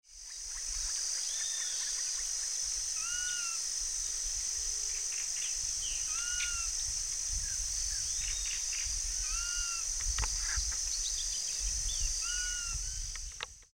Burlisto Pico Canela (Myiarchus swainsoni)
Nombre en inglés: Swainson´s Flycatcher
Localidad o área protegida: Delta del Paraná
Condición: Silvestre
Certeza: Observada, Vocalización Grabada